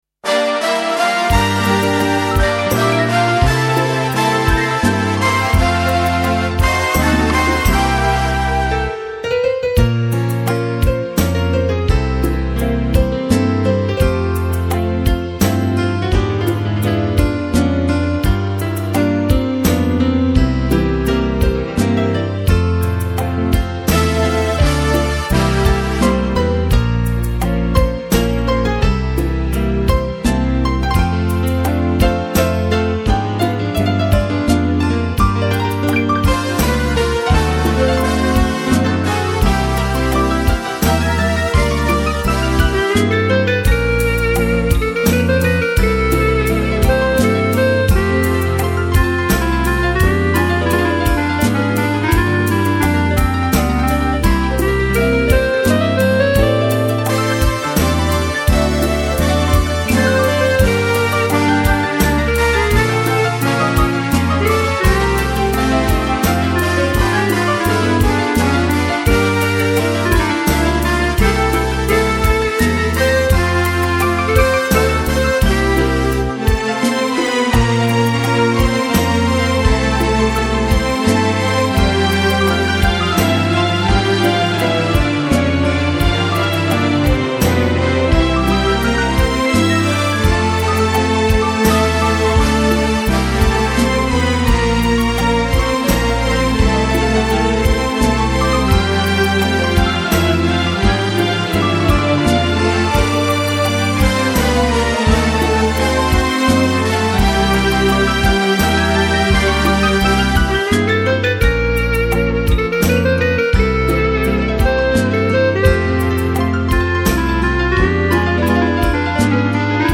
Valzer lento. Piano, orchestra